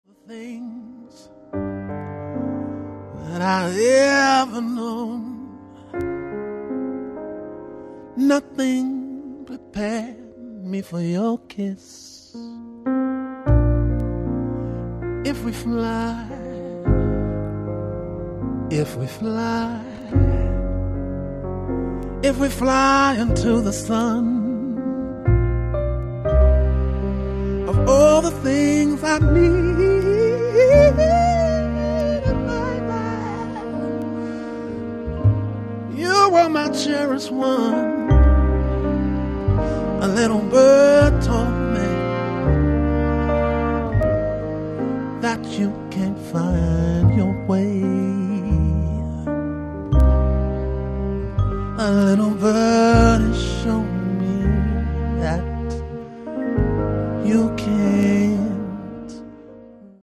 recorded live
with a seven piece band featuring the so soulful voice